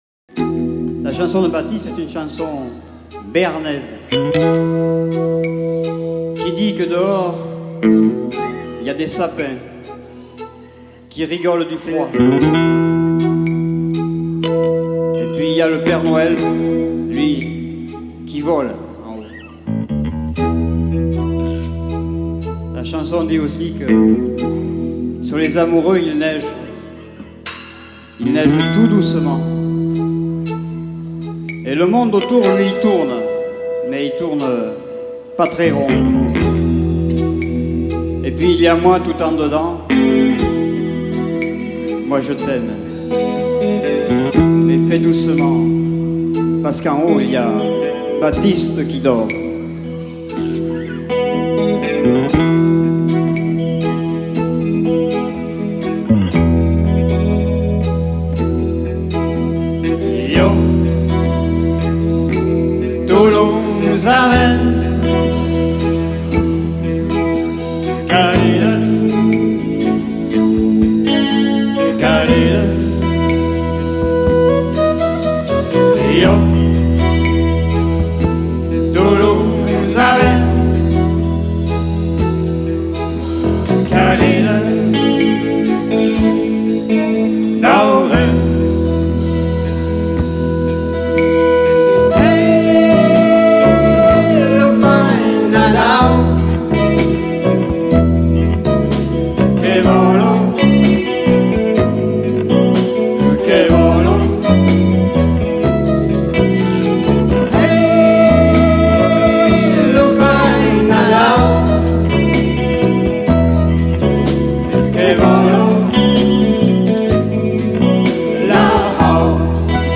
dans un concert à ST avit 33 en 2000
avec les choristes